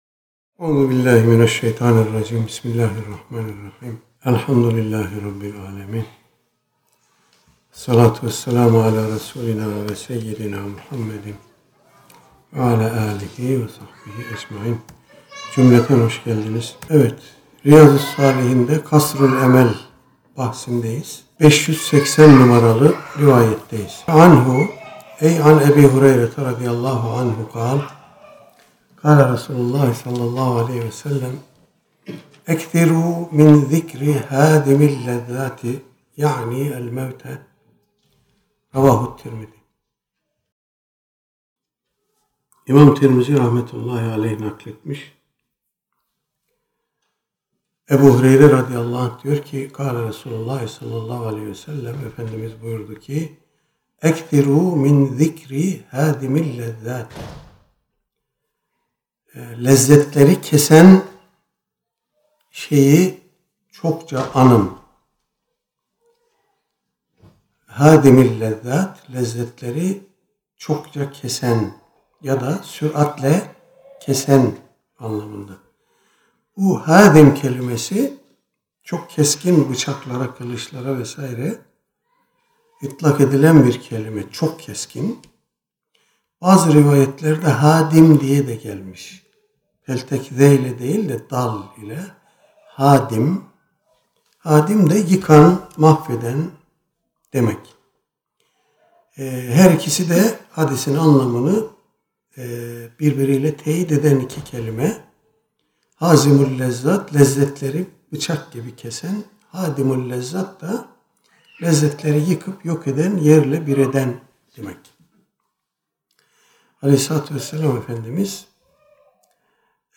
Her hafta mutat olarak icra edilen, Riyâzu’s-Sâlihin seminerimizde bu hafta, “Ölümü Anmak” konusu ele alındı.
Seminer yeri: Uluçınar Vakfı – Pendik.